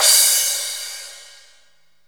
VEC3 Crash